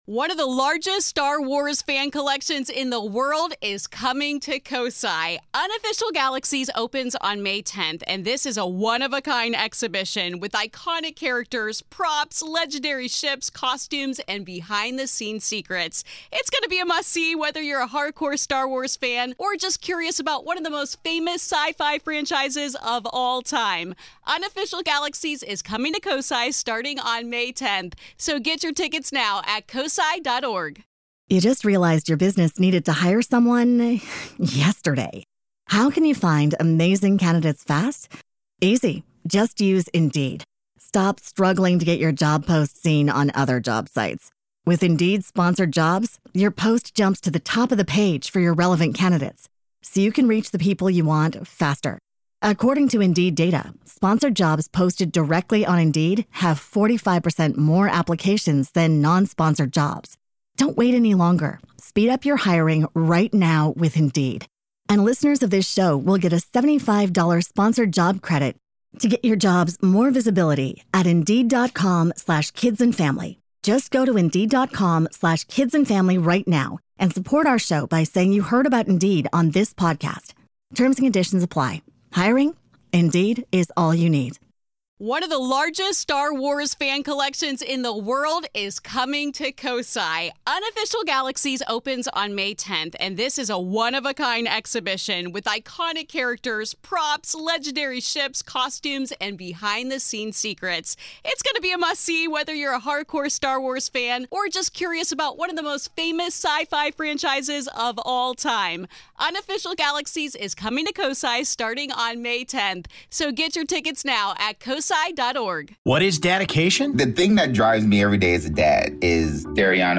In today’s episode of Assumptions we have people with autism confront your assumptions about their identity.